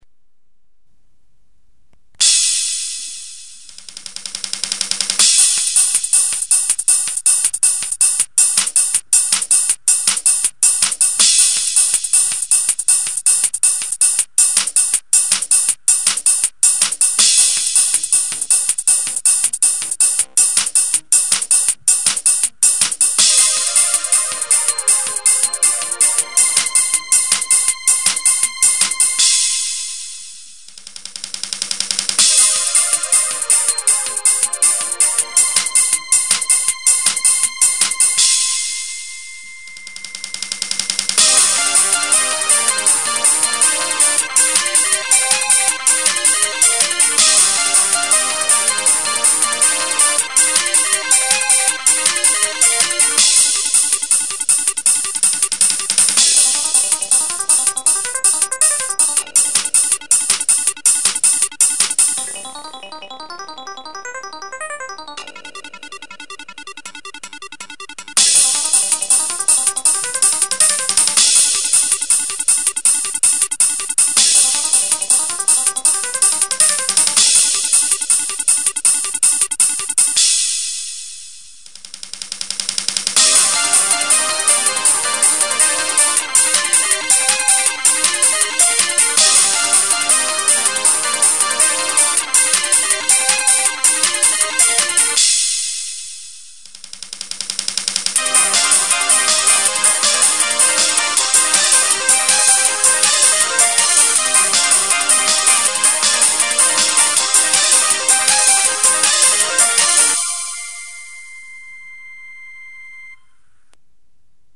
un hymne à la fête